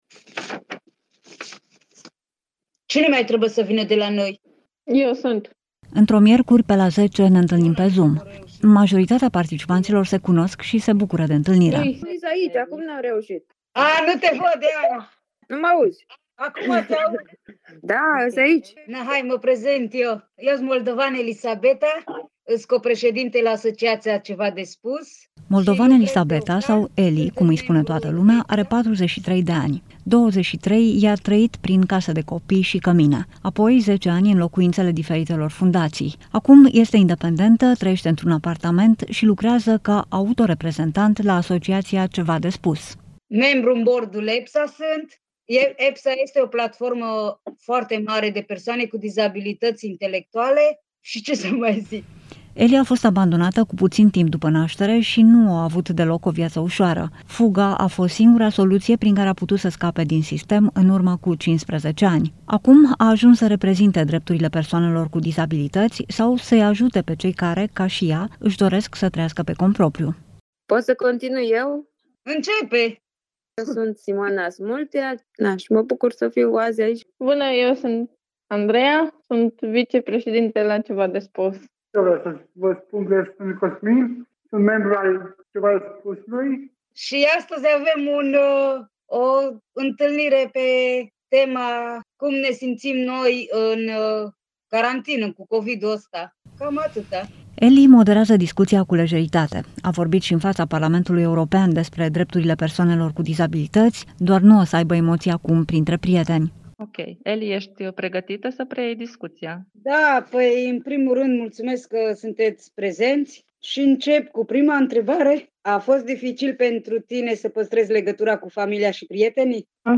Lumea Europa FM: Cei mai izolați dintre noi. Viața persoanelor cu dizabilități, în pandemie | REPORTAJ